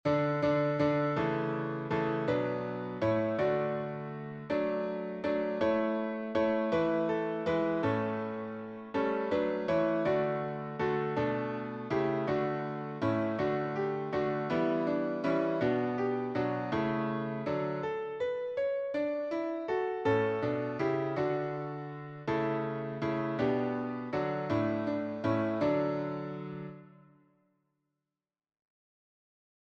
Traditional French carol